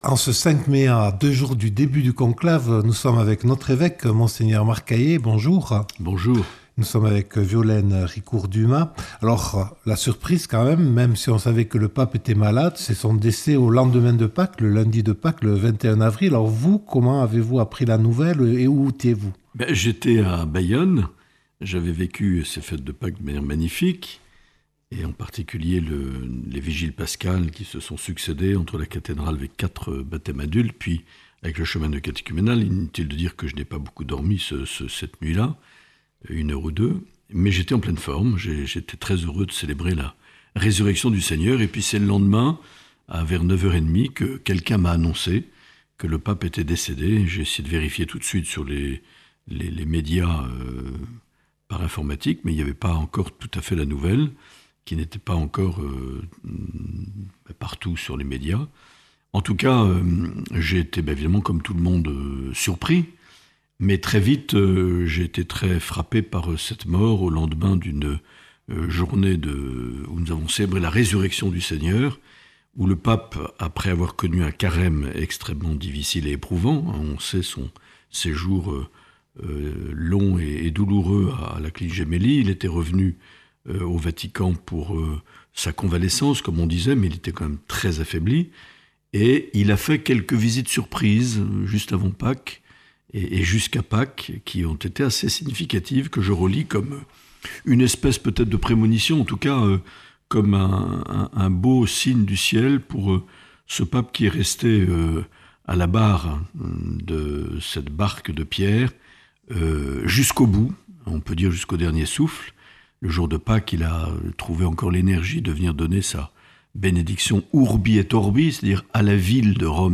Emission mensuelle avec l’Evêque